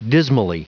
Prononciation du mot dismally en anglais (fichier audio)
Prononciation du mot : dismally